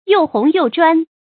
注音：ㄧㄡˋ ㄏㄨㄥˊ ㄧㄡˋ ㄓㄨㄢ
又紅又專的讀法